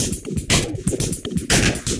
Index of /musicradar/rhythmic-inspiration-samples/120bpm